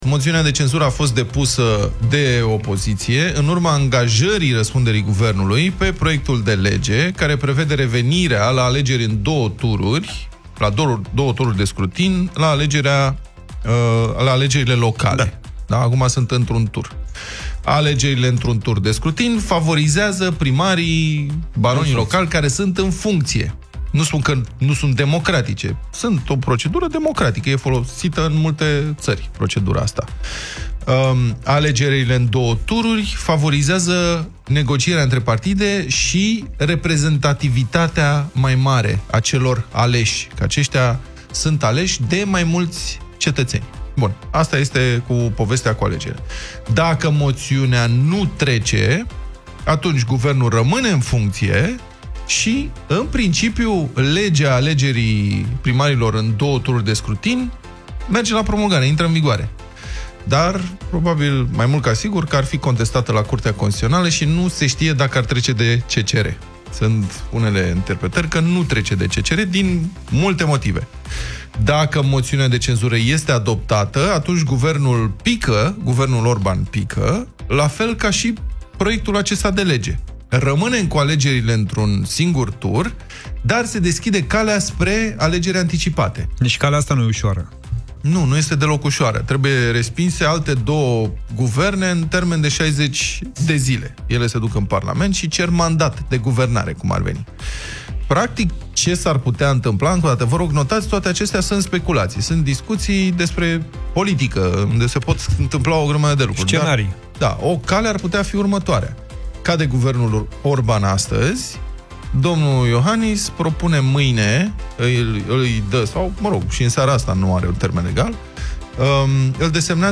Puteți reasculta Deșteptarea din secțiunea podcast Europa FM, cu ajutorul aplicației gratuite pentru Android și IOS Europa FM sau direct în Spotify și iTunes.